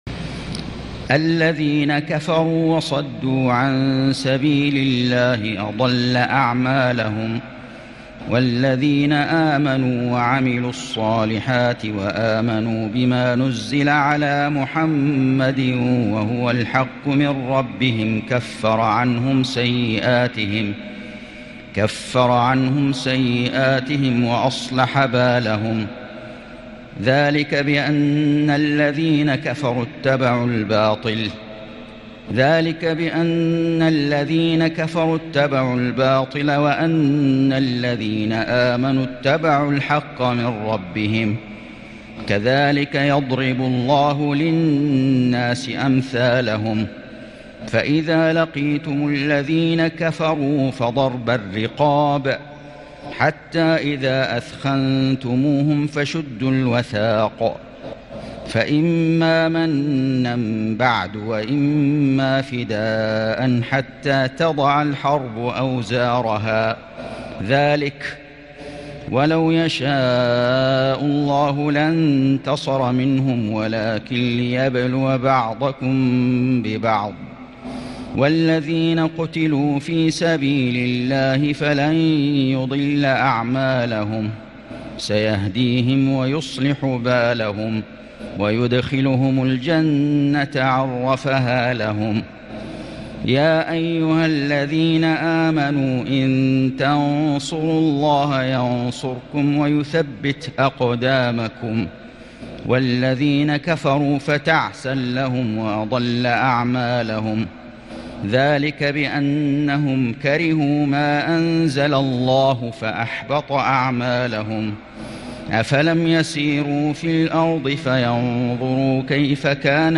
سورة محمد > السور المكتملة للشيخ فيصل غزاوي من الحرم المكي 🕋 > السور المكتملة 🕋 > المزيد - تلاوات الحرمين